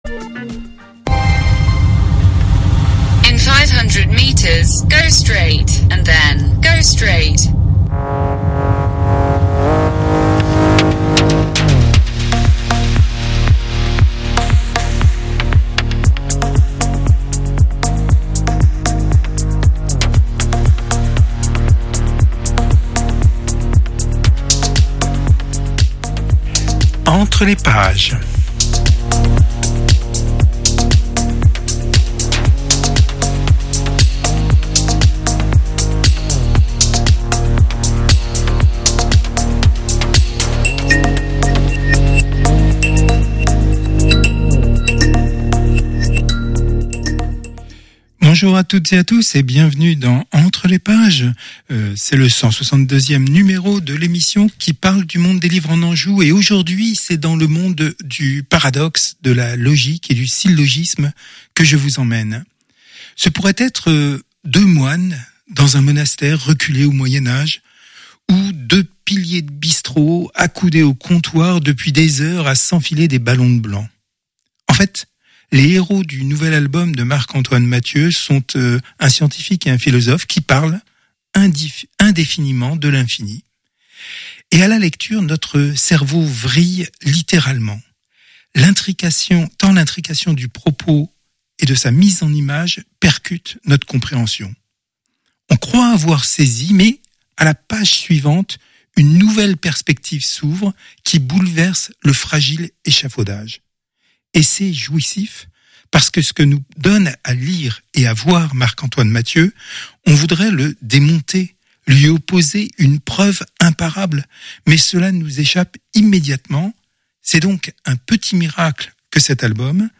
ENTRE LES PAGES, c’est une heure consacrée à l’univers des livres en Anjou. Interviews, reportages, enquêtes, sont au menu.